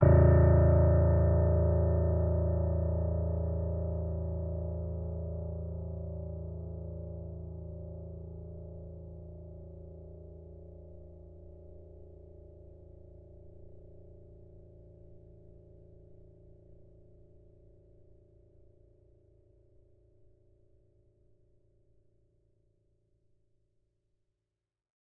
sampler example using salamander grand piano
A0.ogg